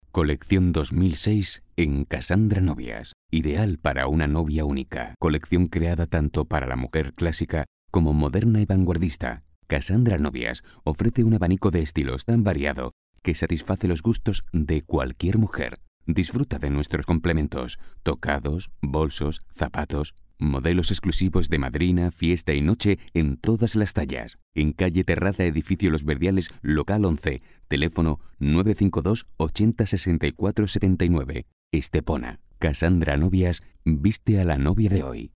Spot publicitario realizado para la Televisión de Estepona.
Spot publicitario realizado para Coín Televisión.